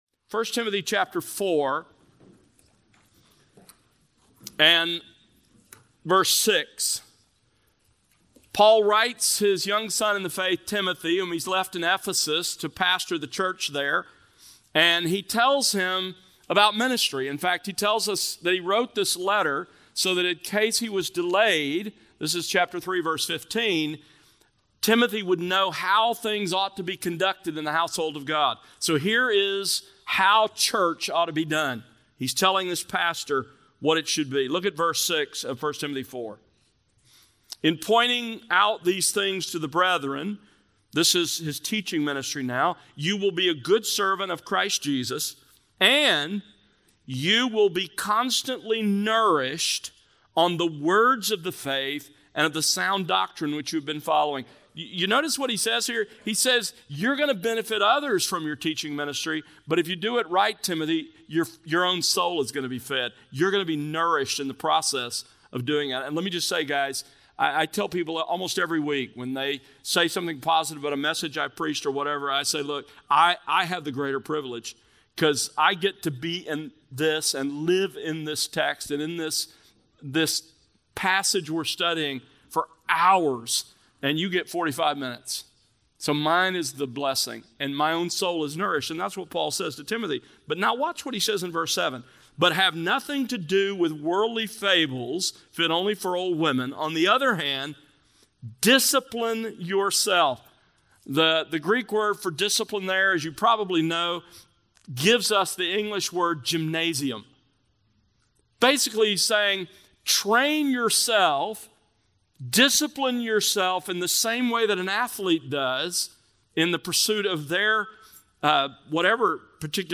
Institutes of Theology | Session 14 - Spiritual Disciplines + Q&A